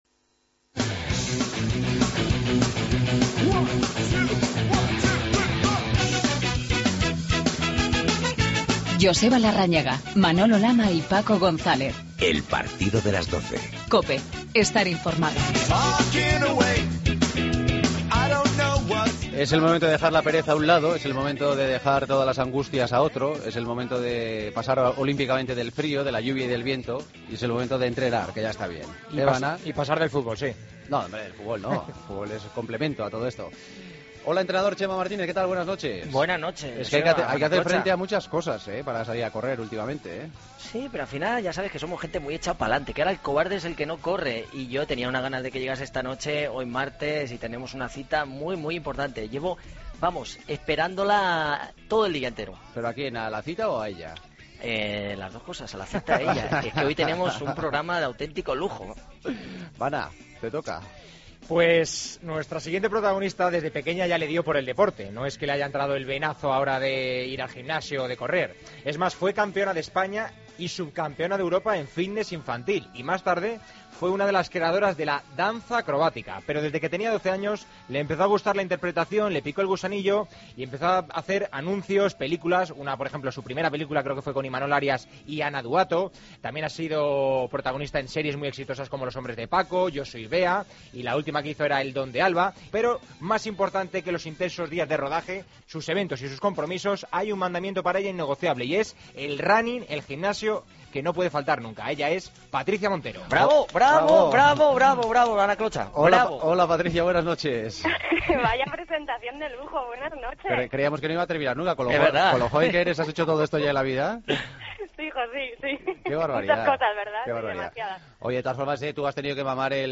AUDIO: Además de los consejos semanales de nuestro atleta, contamos con una runner especial: la actriz y bailarina Patricia Montero.